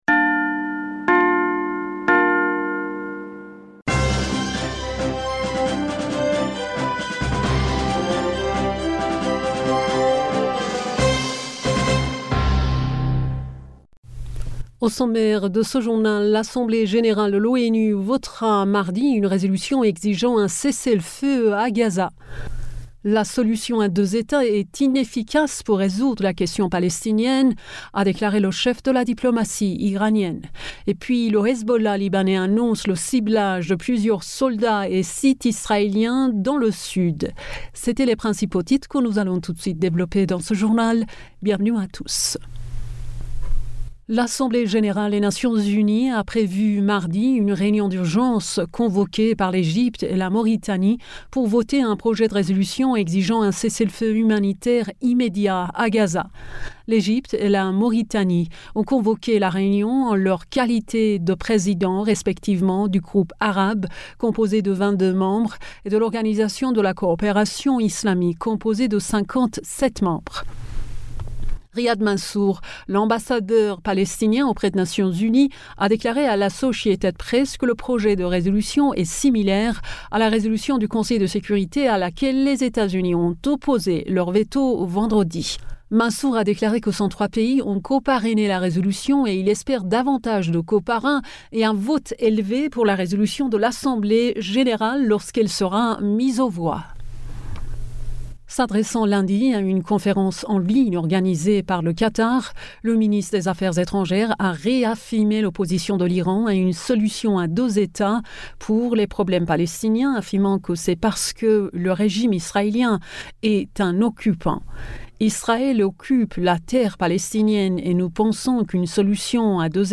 Bulletin d'information du 12 Decembre 2023